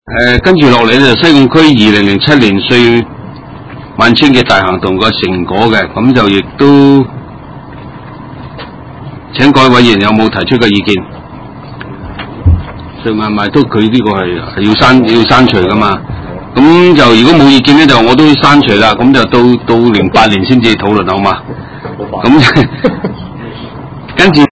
地點：西貢區議會會議室